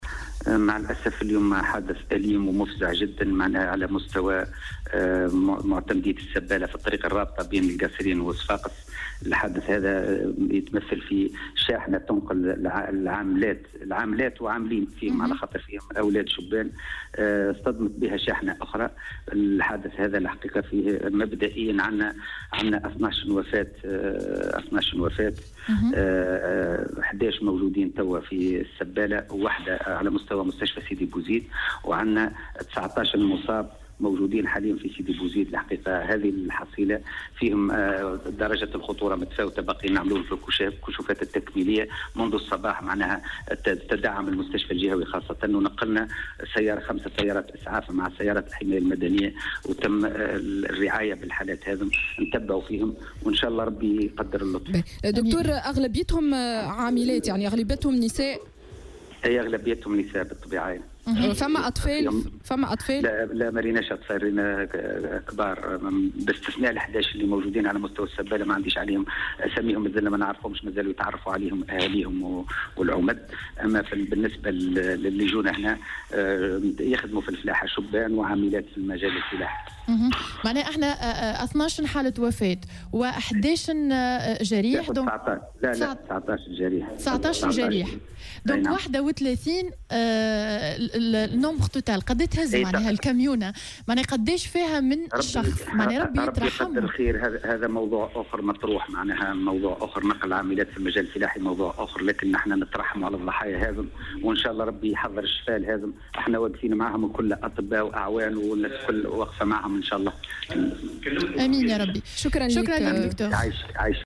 وأضاف في اتصال هاتفي مع "الجوهرة أف أم" أنه تم نقل 11 جثة إلى المستشفى المحلي بالسبالة وجثة واحدة إلى المستشفى الجهوي بسيدي بوزيد، مشيرا أيضا إلى أنه تم تخصيص 5 سيارات إسعاف إلى جانب سيارات الحماية المدنية.